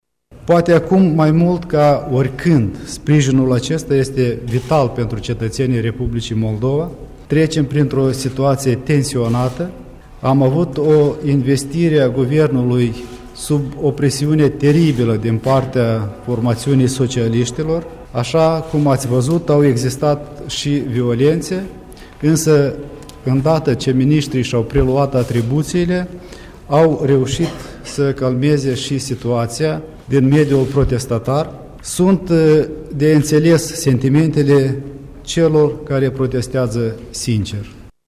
Premierul Republicii Moldova, Pavel Filip: